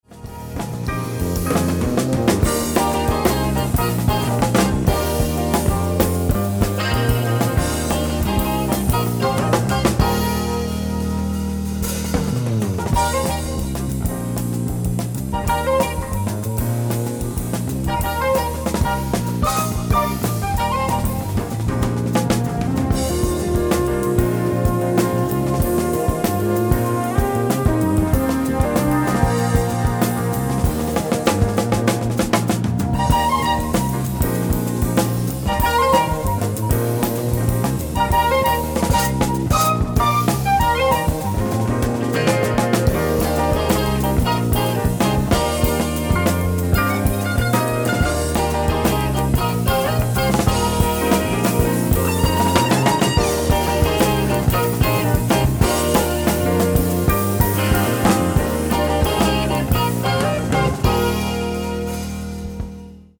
ライブ・アット・ブレーメン、ドイツ 03/13/1978
※試聴用に実際より音質を落としています。